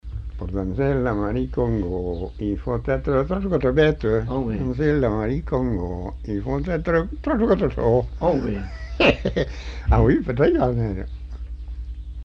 Lieu : Simorre
Genre : chant
Effectif : 1
Type de voix : voix d'homme
Production du son : chanté
Danse : congo